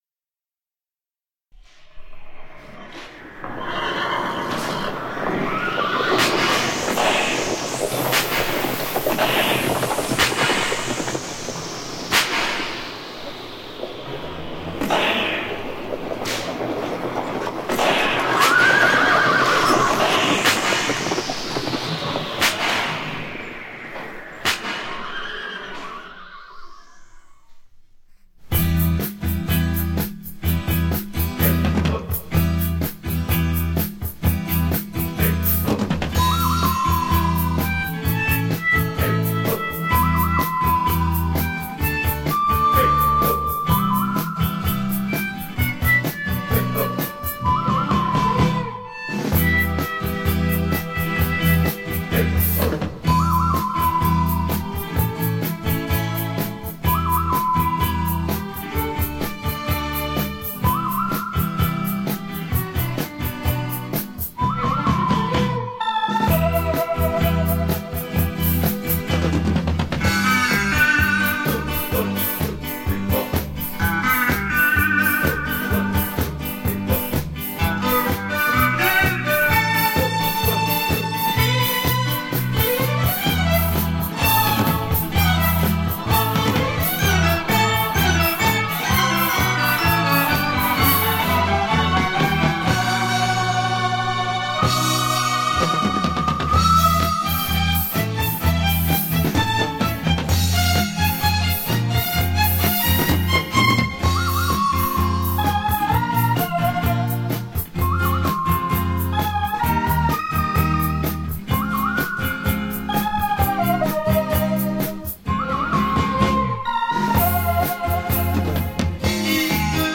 奥斯卡电子琴音乐